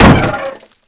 Index of /server/sound/vcmod/collision/light